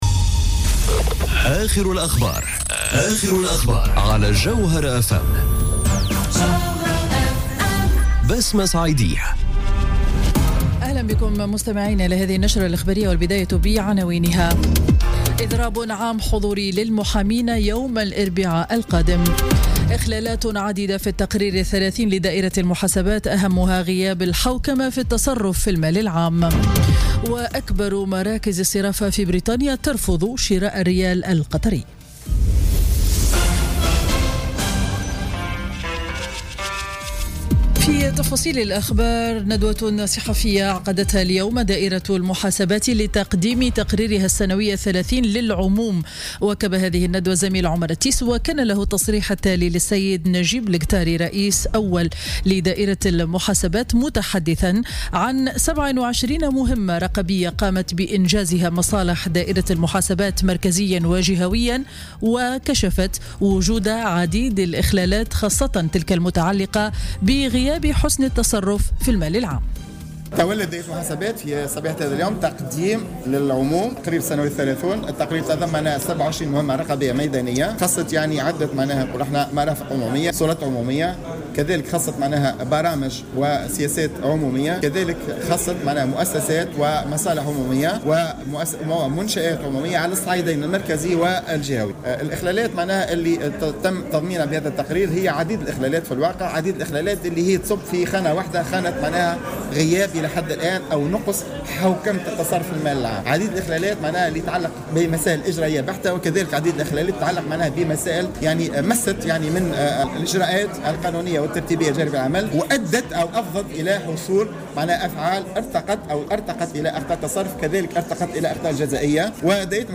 نشرة أخبار منتصف النهار ليوم الخميس 29 جوان 2017